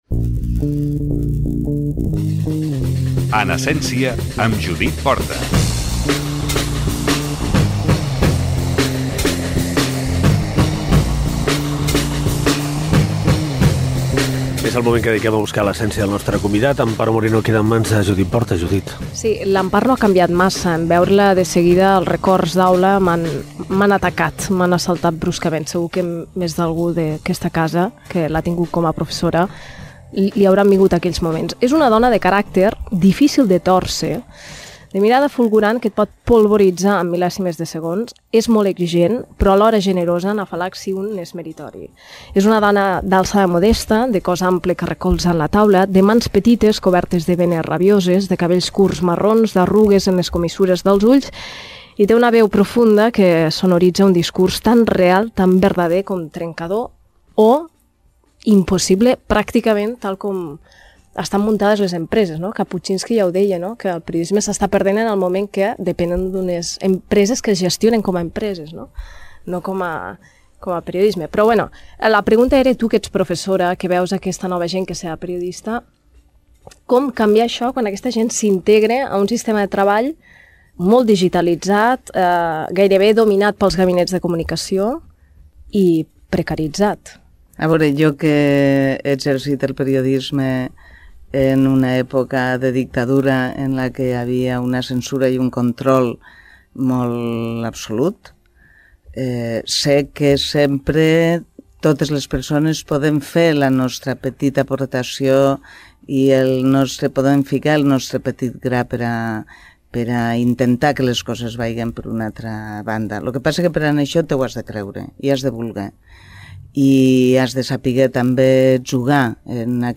Espai "En essència", perfil biogràfic i entrevista